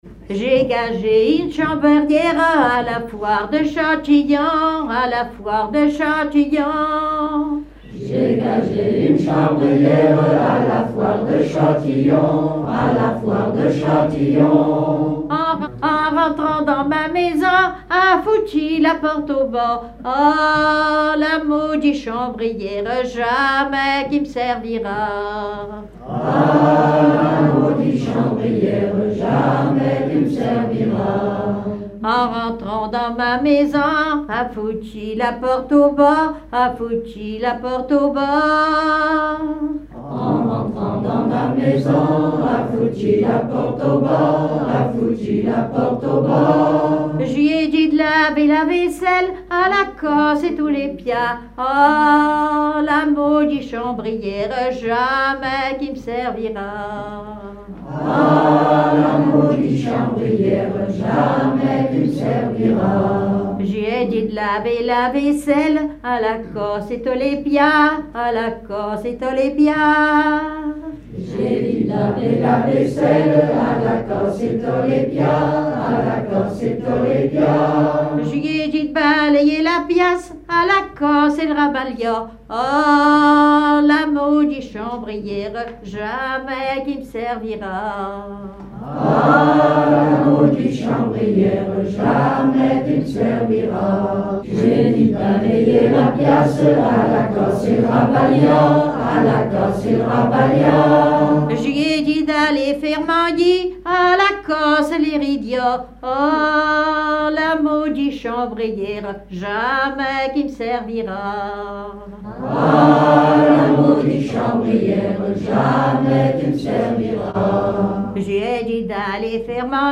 Genre laisse
Veillée (version Revox)
Pièce musicale inédite